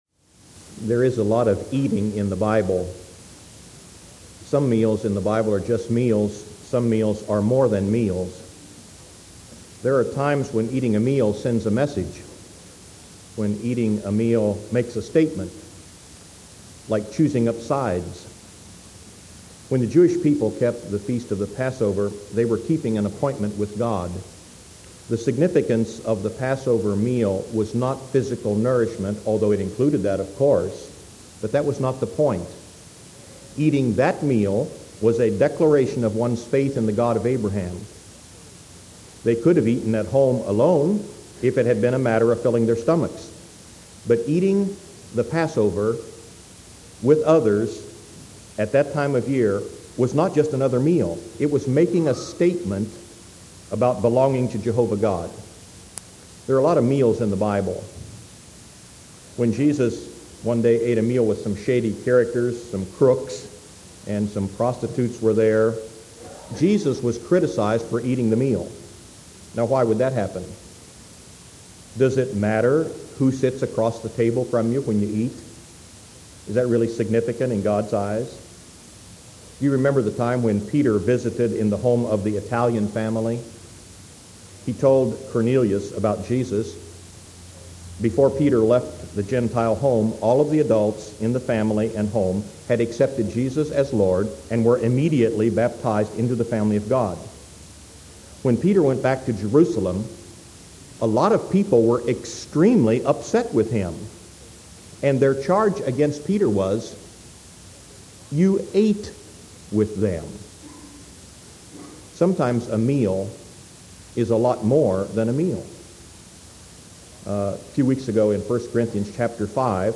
Minor note: the date on the cassette was 12/2/1997, but that was a Tuesday.